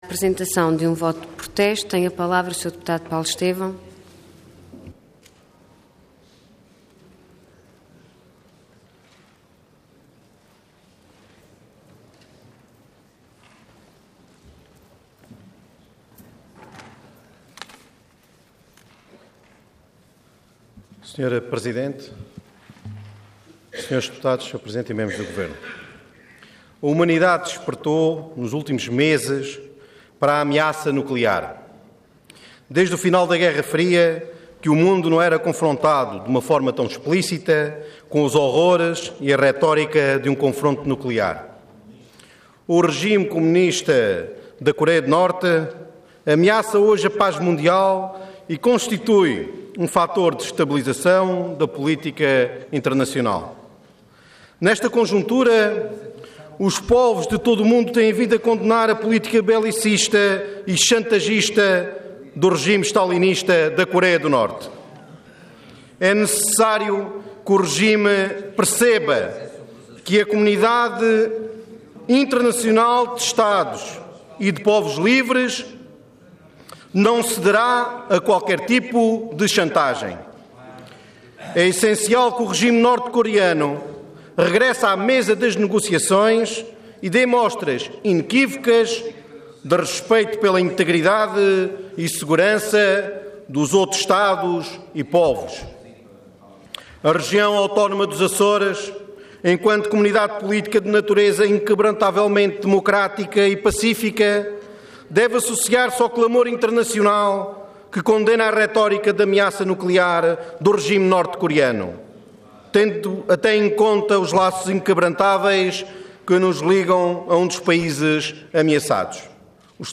Detalhe de vídeo 18 de abril de 2013 Download áudio Download vídeo Diário da Sessão Processo X Legislatura Contra a política de ameaça nuclear desenvolvida pelo regime norte-coreano, sublinhando, ao mesmo tempo, a importância do diálogo para a resolução dos conflitos internacionais. Intervenção Voto de Protesto Orador Paulo Estêvão Cargo Deputado Entidade PPM